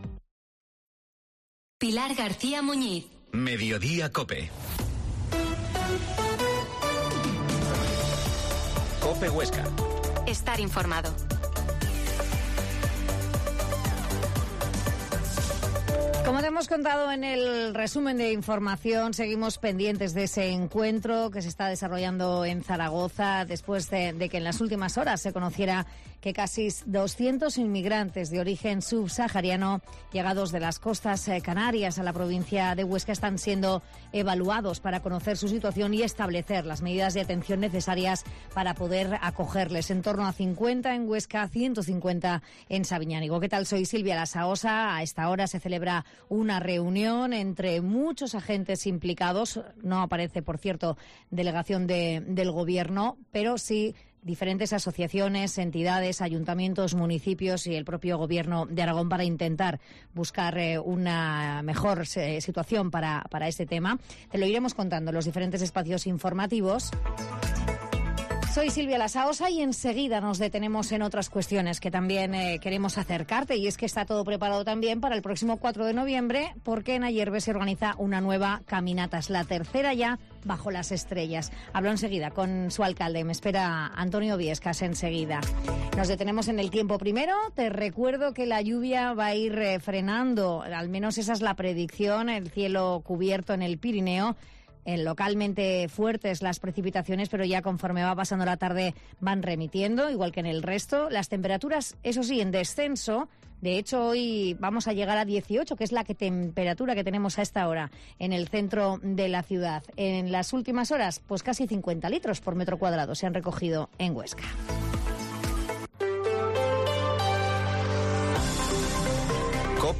Mediodia en COPE Huesca 13.20 Entrevista al alcalde de Ayerbe, Antonio Biescas